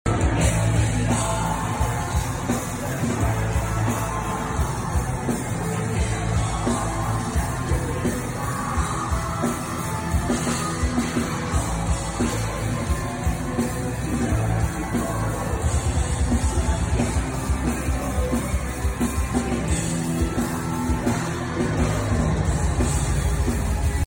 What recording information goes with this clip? LIVE 24 seconds 0 Downloads Morbid